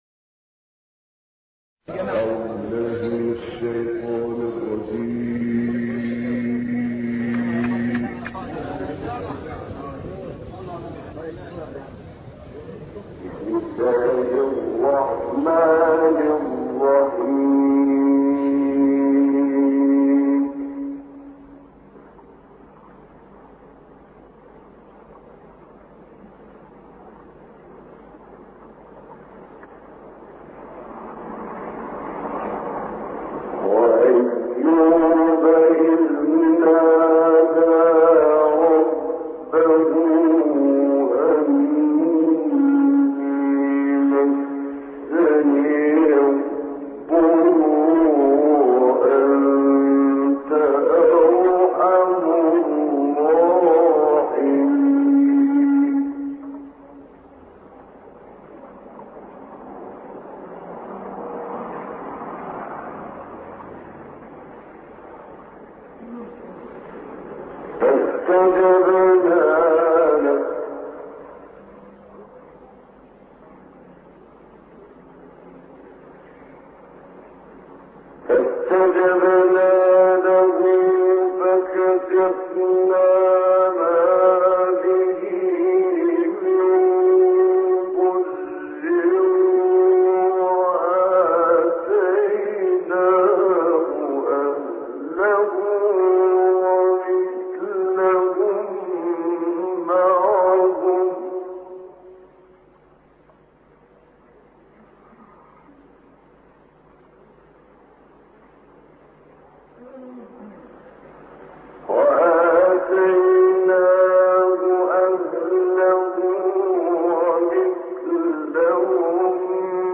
دانلود قرائت سوره انبیا آیات 83 تا آ خر - استاد محمد عمران